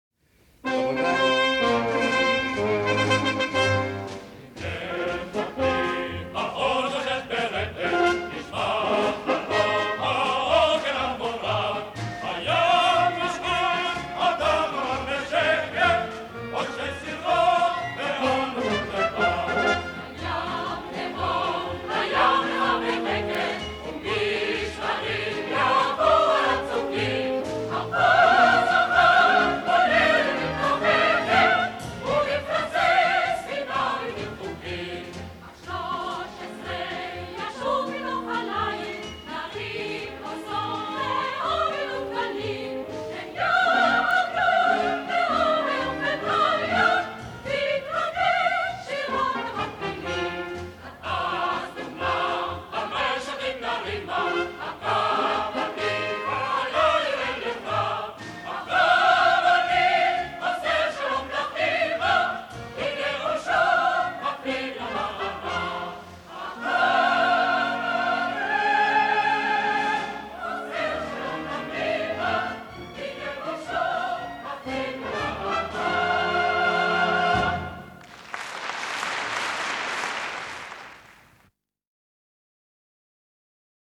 Еще одно старое израильское исполнение, да не простое.